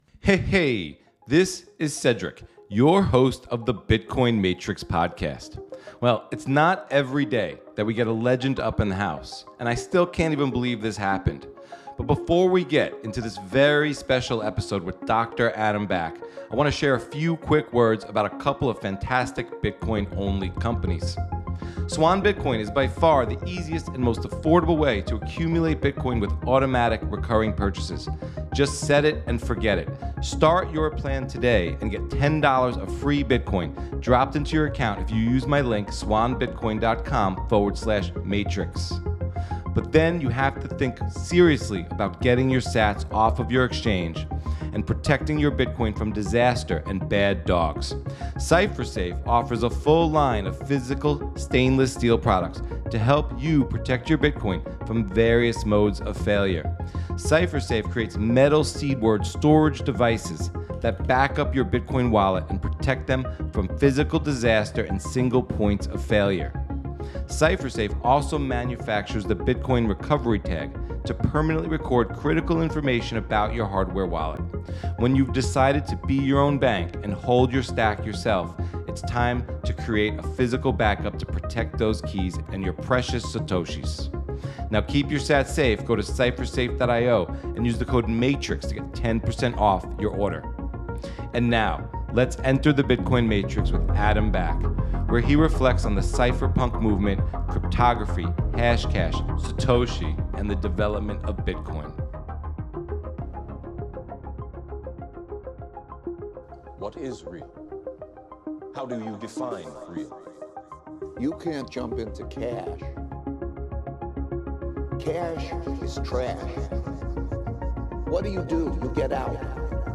In this very special episode Dr. Adam Back, the CEO and co-founder of Blockstream has a very candid conversation as Adam reflects on the cypherpunk movement, cryptography, privacy, ecash, inventing hashcash (used in Bitcoin mining), Bitcoin and Satoshi.